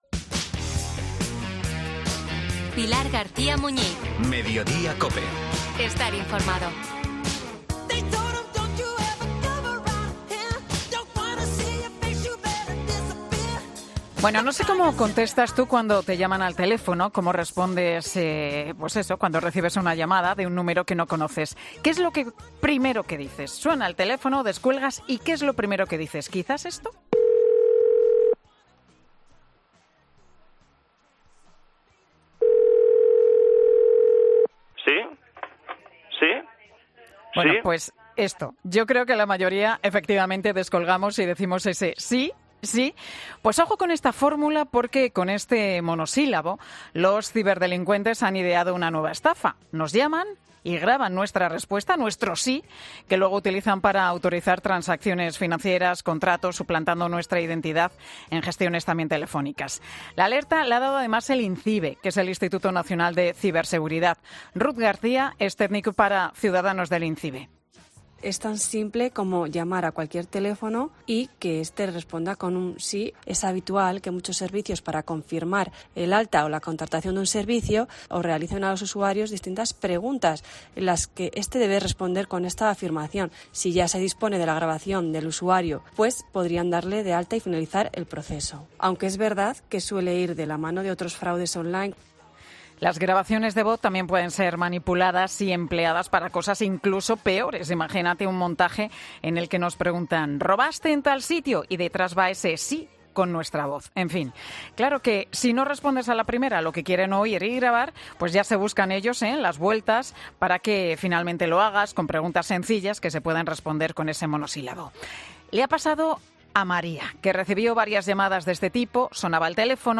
En 'Mediodía COPE' hablamos con una víctima de esta estafa y dos expertos para explicarnos en qué consiste y cómo podemos actuar ante ella